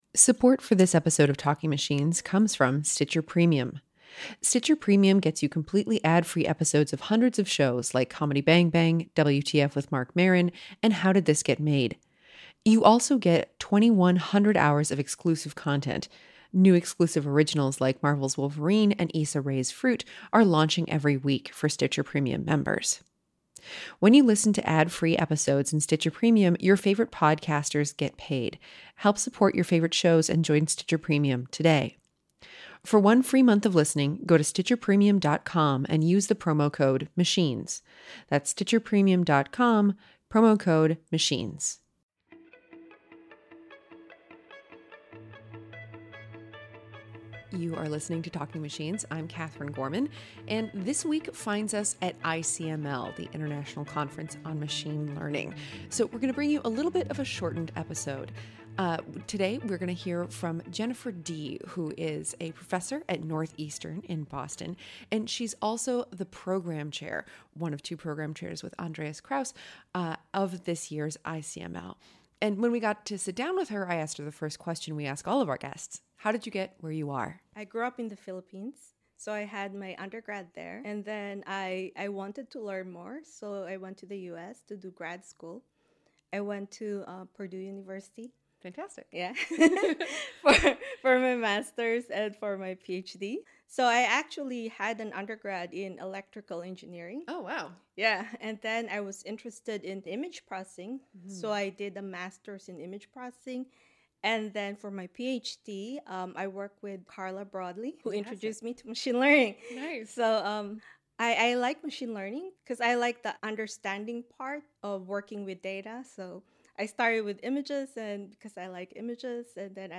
Season four episode twelve finds us at ICML!